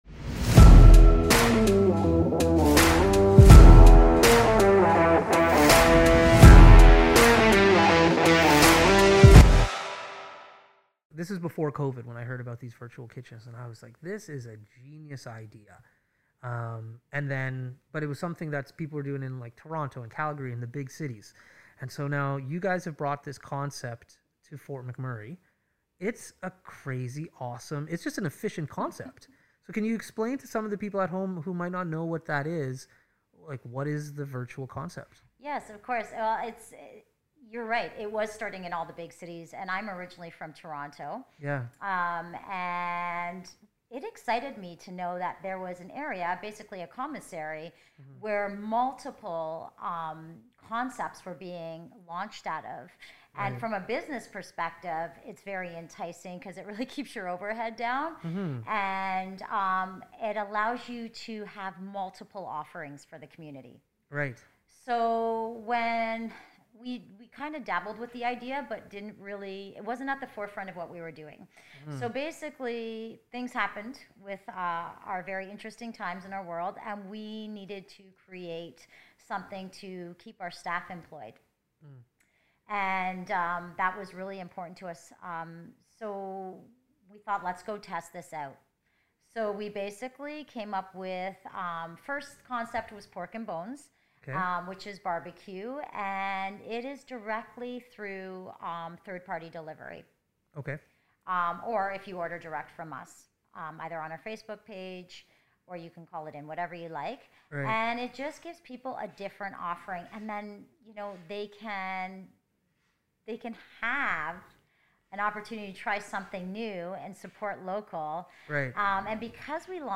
*DISCLAIMER: the introduction is missing.